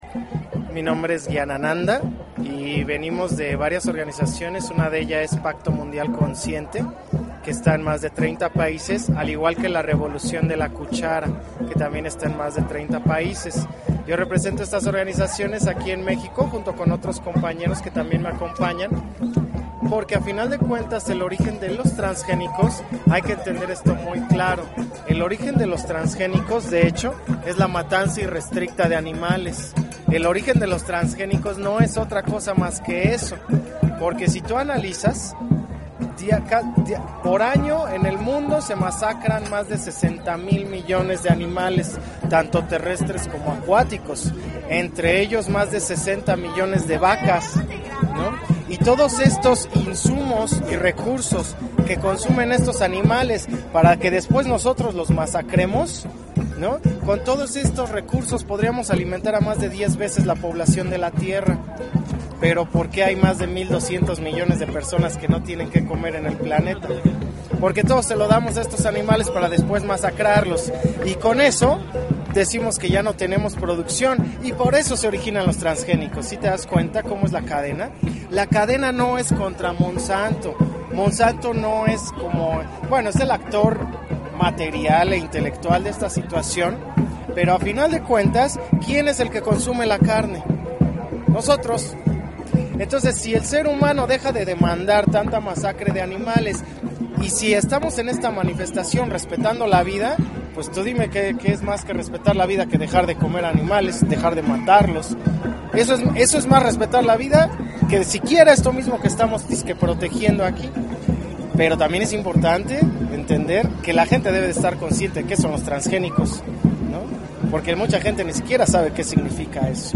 Entrevista con el colectivo Revolución de la Cuchara